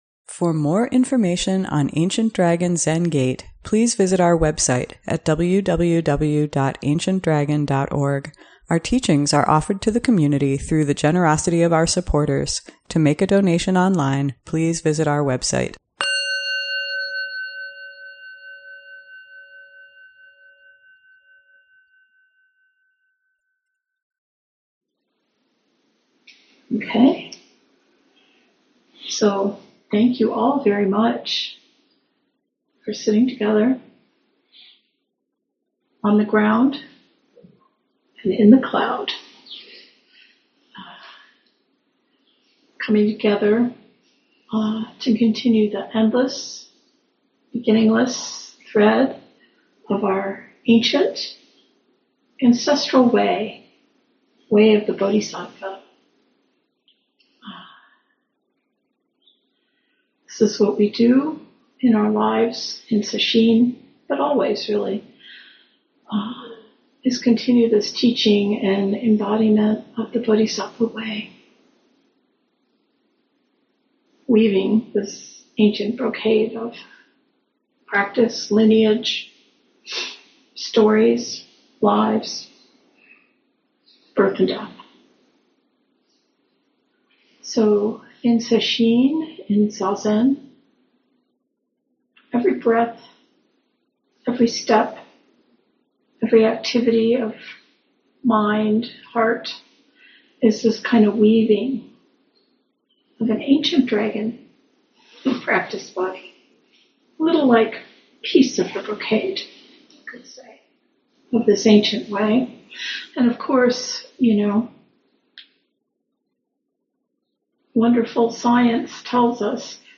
Sunday Morning Dharma Talk